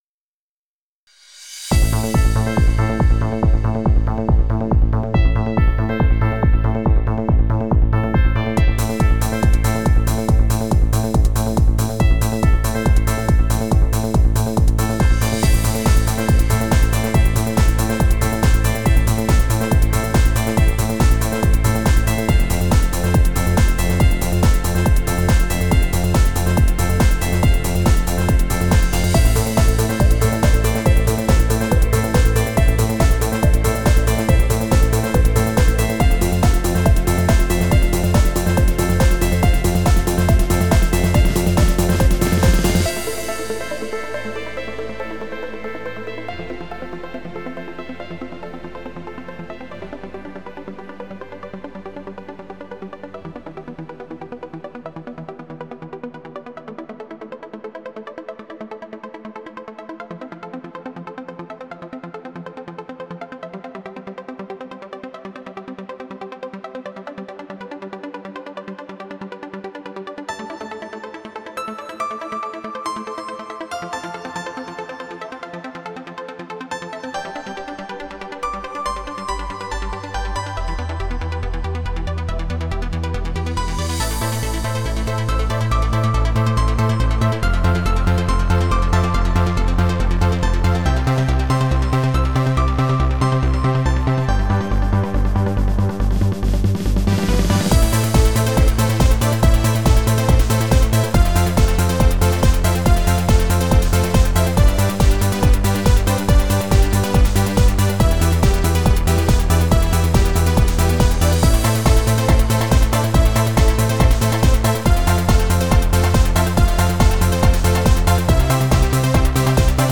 Instrumental music